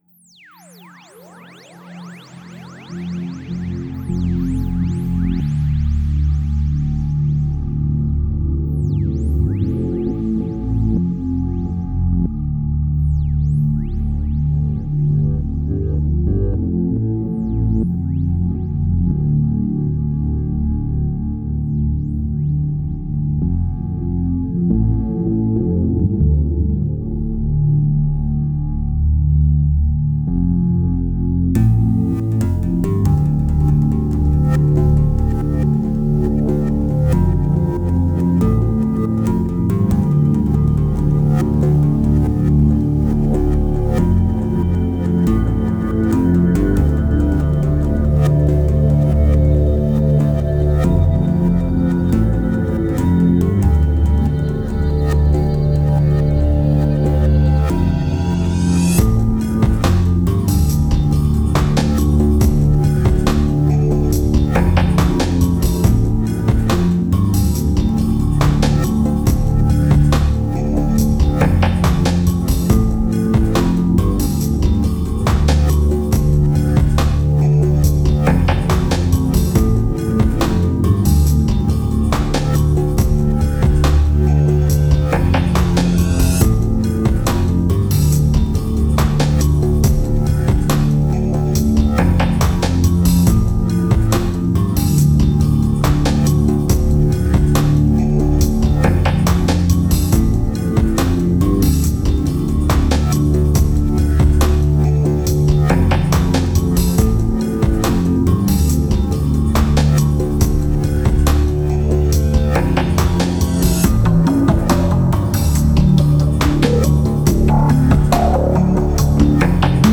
Genre: Ambient, IDM, Chillout.